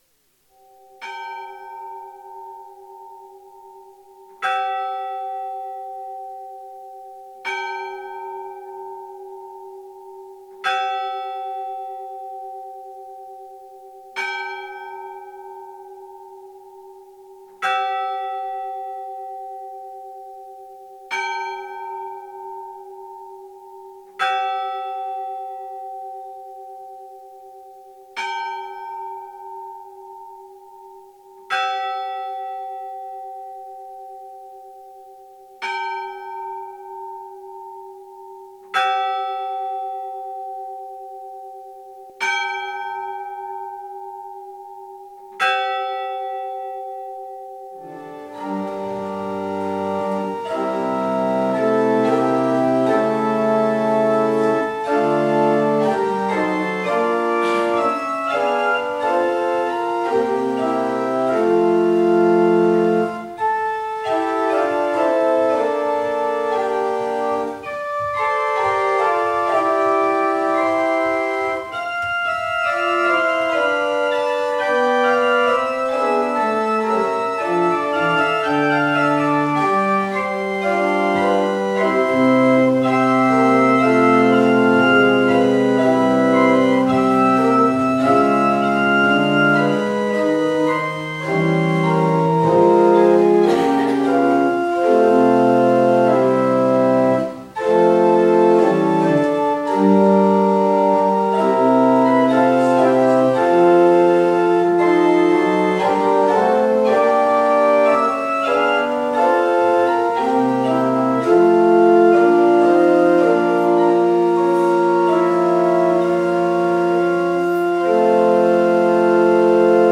Jumalateenistus 29. juuni 2025